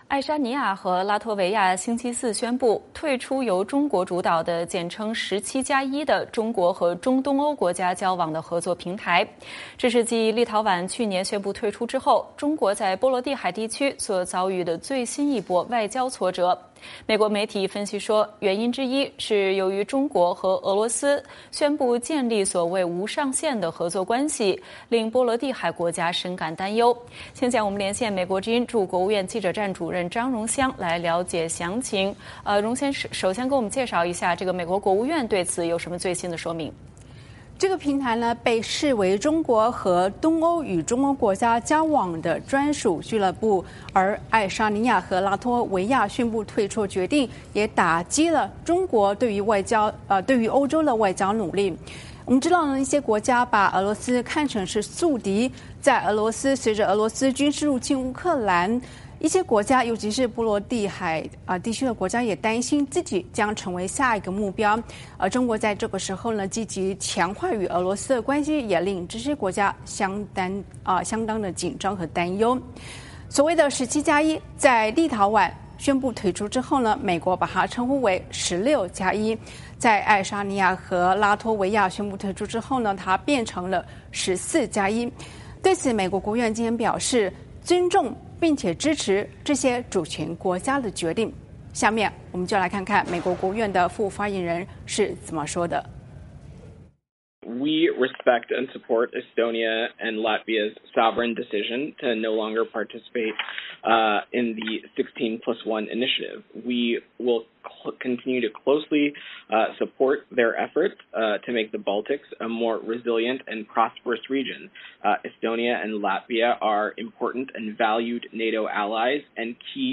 VOA连线: 美支持爱沙尼亚、拉脱维亚退出17+1集团决定